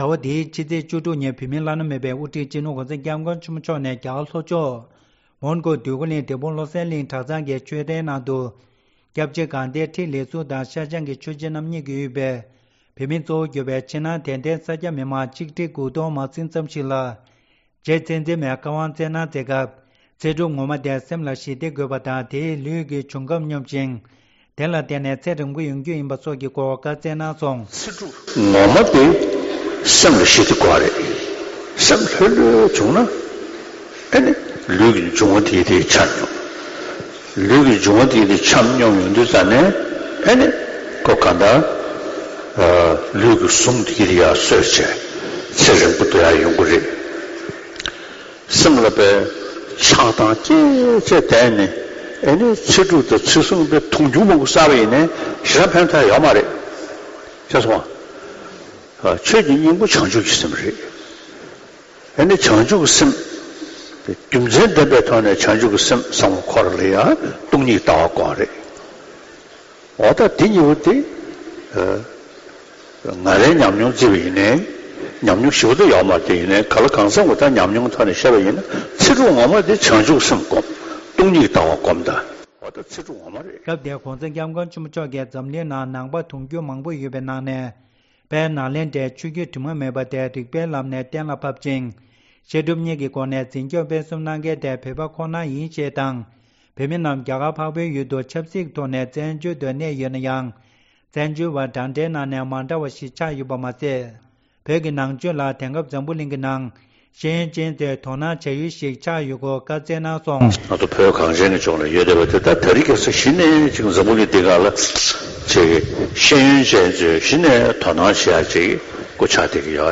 ༧གོང་ས་སྐྱབས་མགོན་ཆེན་པོ་མཆོག་གིས་འབྲས་སྤུངས་བློ་གསལ་གླིང་དུ་རྗེ་ཚེ་འཛིན་མའི་ཚེ་དབང་བསྩལ་སྐབས། ༢༠༡༩།༡༢།༡༦ ༧གོང་ས་སྐྱབས་མགོན་ཆེན་པོ་མཆོག་གིས་འབྲས་སྤུངས་བློ་གསལ་གླིང་དུ་རྗེ་ཚེ་འཛིན་མའི་ཚེ་དབང་བསྩལ་སྐབས། ༢༠༡༩།༡༢།༡༦
སྒྲ་ལྡན་གསར་འགྱུར། སྒྲ་ཕབ་ལེན།